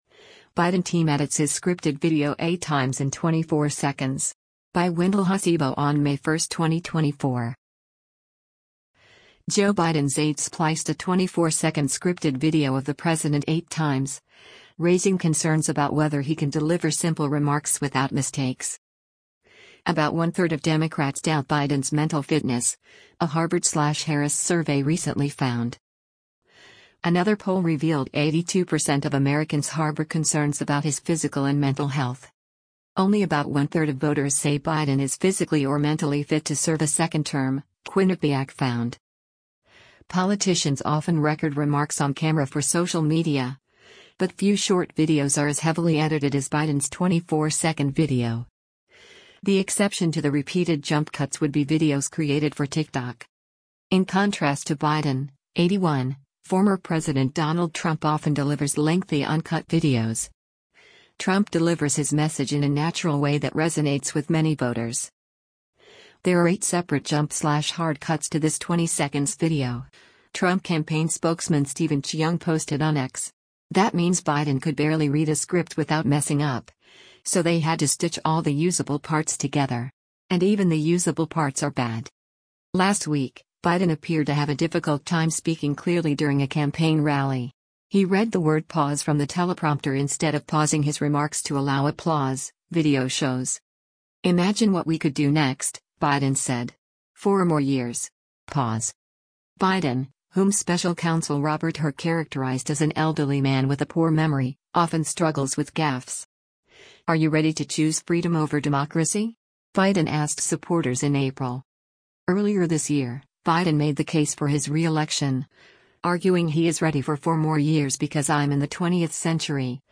Biden Team Edits His Scripted Video 8 Times in 24 Seconds
Joe Biden’s aides spliced a 24-second scripted video of the president eight times, raising concerns about whether he can deliver simple remarks without mistakes.
Politicians often record remarks on camera for social media, but few short videos are as heavily edited as Biden’s 24-second video.